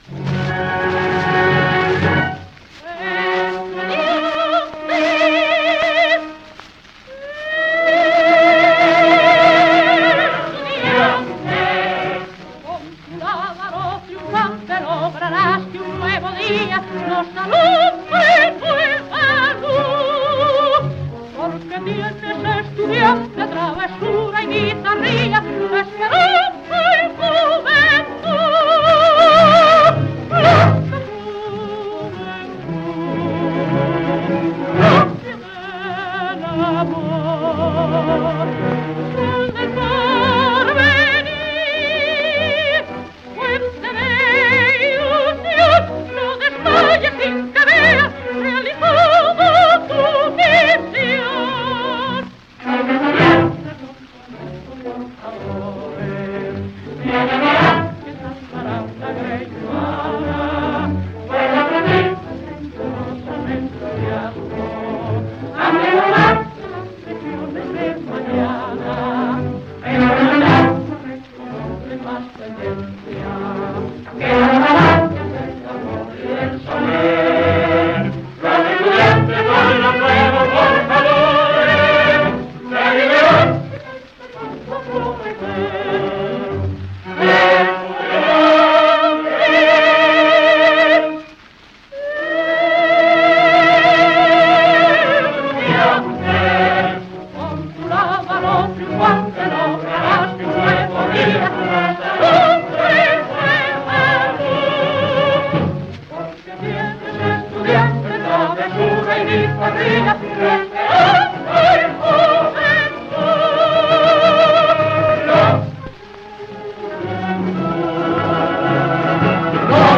coro [78 rpm]